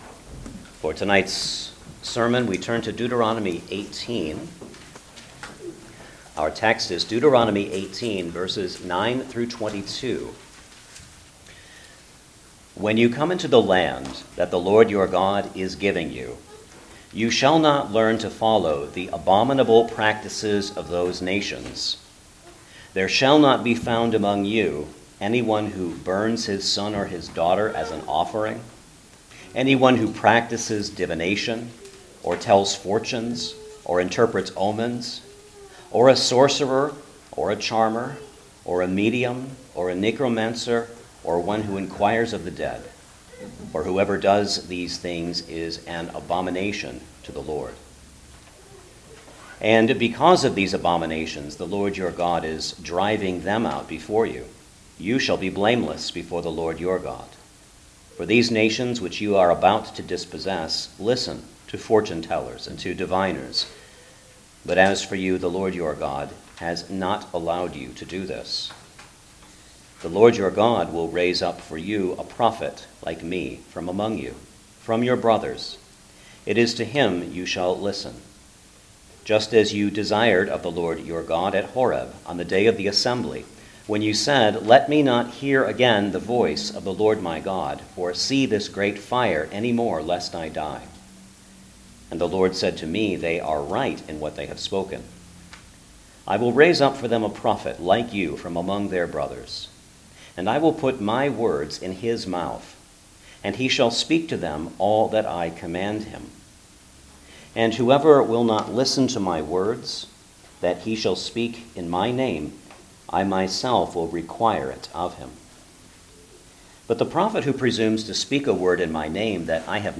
Deuteronomy Passage: Deuteronomy 18:9-22 Service Type: Sunday Evening Service Download the order of worship here .